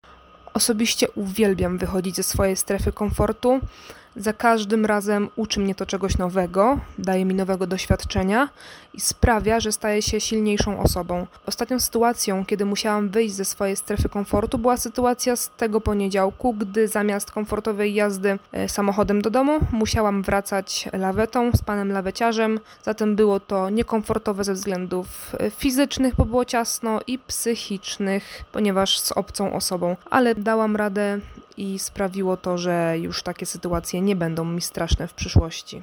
Zapytaliśmy studentki, czy były kiedyś w sytuacji, w której musiały opuścić swoją strefę komfortu i jak poradziły sobie z dostosowaniem do nowej sytuacji: